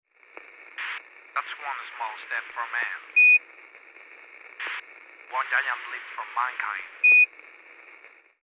Voz de un astronauta
Sonidos: Comunicaciones
Sonidos: Voz humana